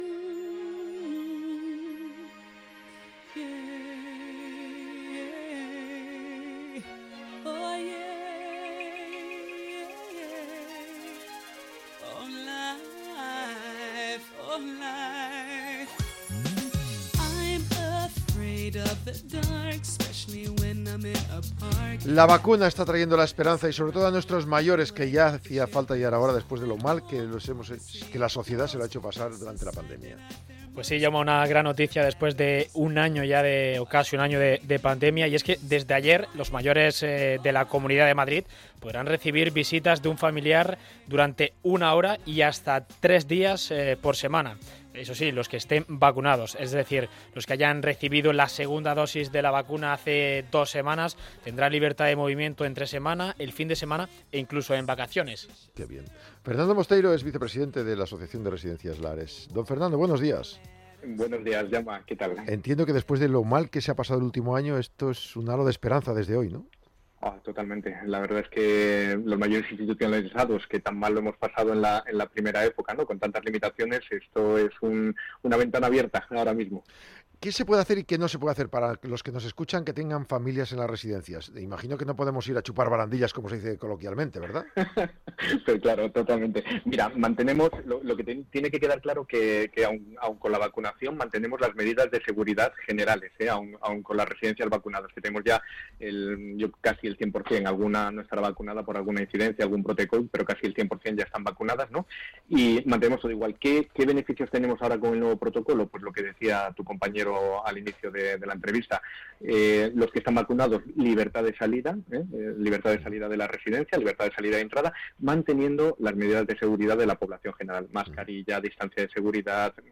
Una entrevista actual que nos acerca a la realidad de los mayores institucionalizados.